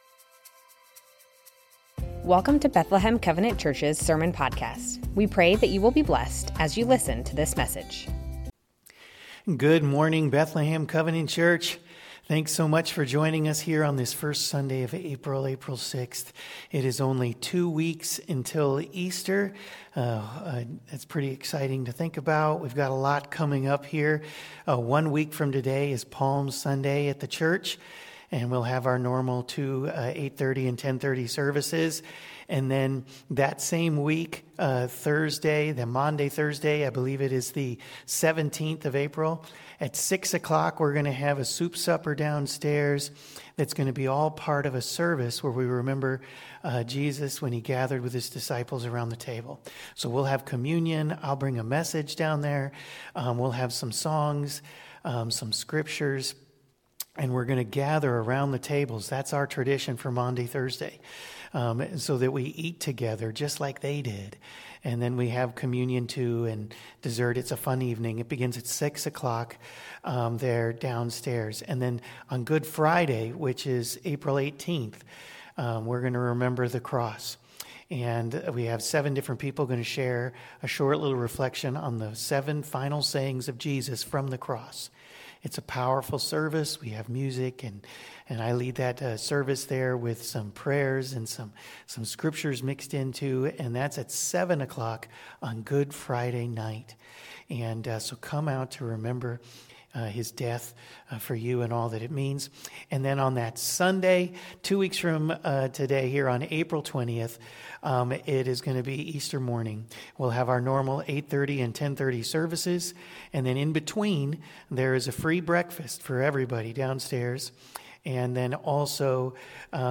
Bethlehem Covenant Church Sermons Matthew 9:35-10:20 - The Mission Apr 06 2025 | 00:32:34 1x 00:00 / 00:32:34 Subscribe Share Spotify RSS Feed Share Link Embed